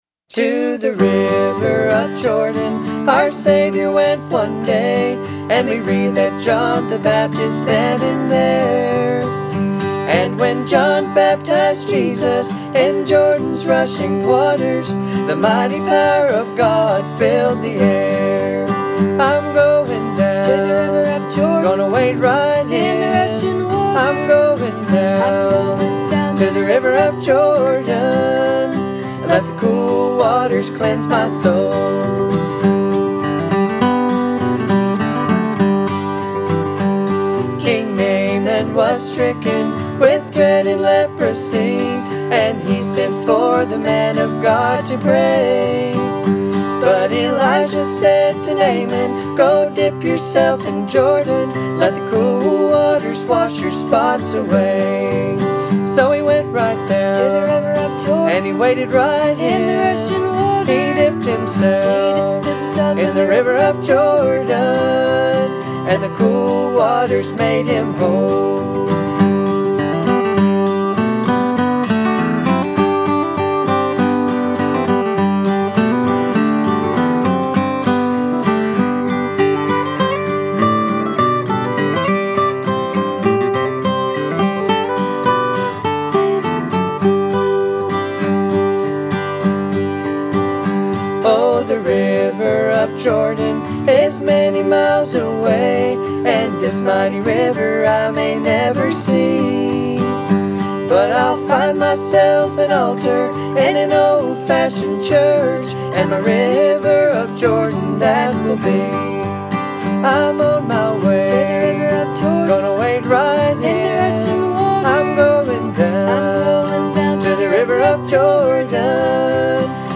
Clawhammer Banjo
Harmony & Double Guitar